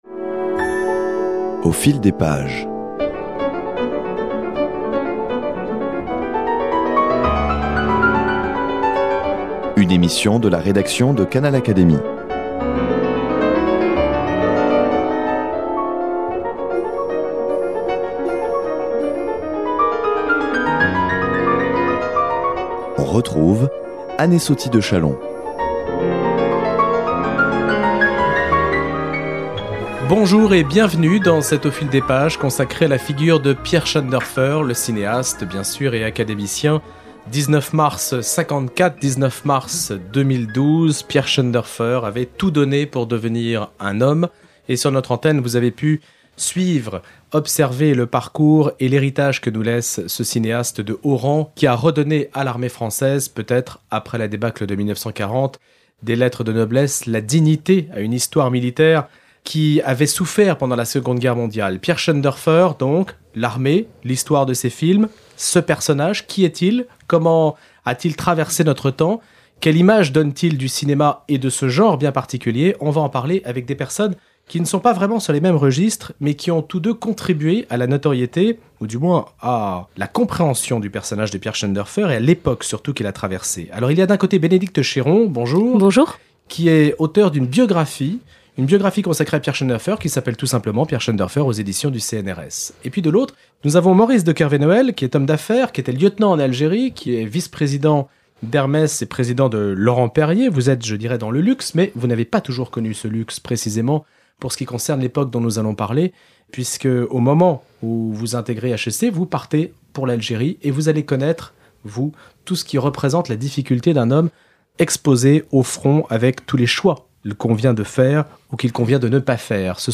Cette émission a été enregistrée fin mai 2012, après la mort de l'académicien (le 13 mars 2012).